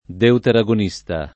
vai all'elenco alfabetico delle voci ingrandisci il carattere 100% rimpicciolisci il carattere stampa invia tramite posta elettronica codividi su Facebook deuteragonista [ deutera g on &S ta ] s. m. e f.; pl. m. -sti